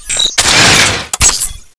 Index of /sound/icsdm_new/privilege/guns
cminigun_reload.wav